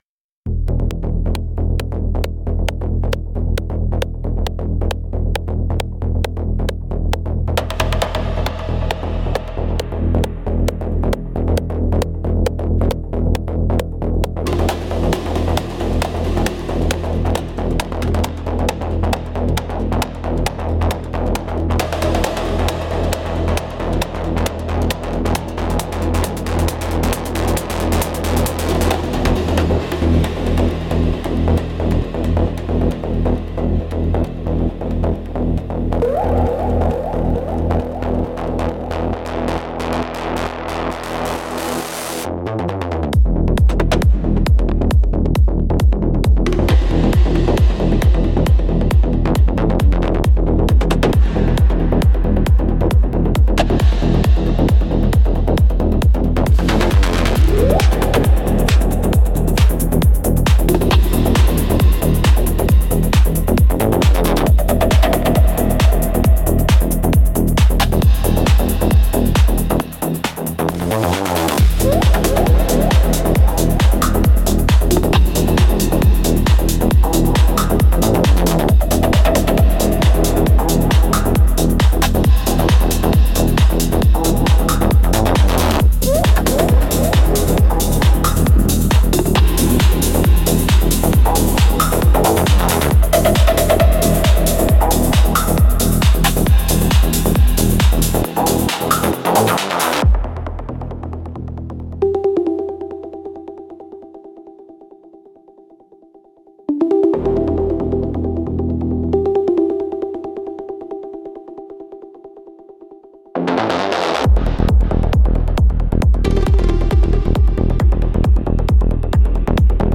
Instrumentals - Concrete Forest Drumming